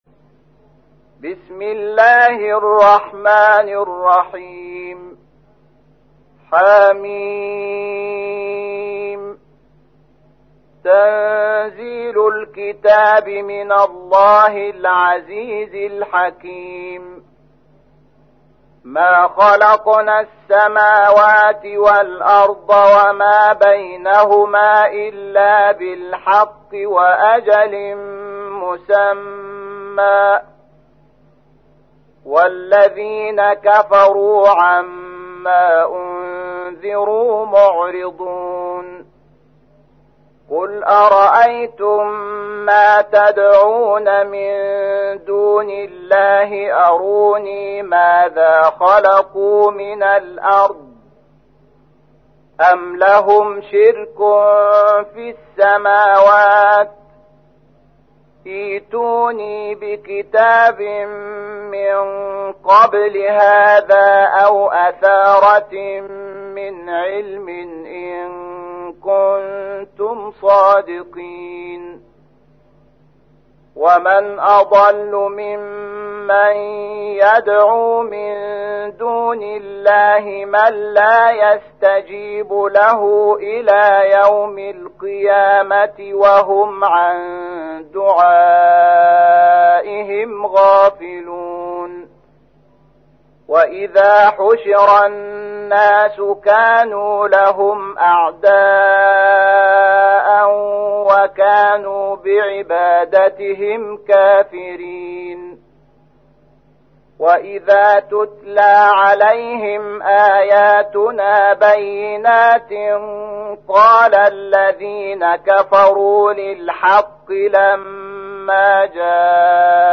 تحميل : 46. سورة الأحقاف / القارئ شحات محمد انور / القرآن الكريم / موقع يا حسين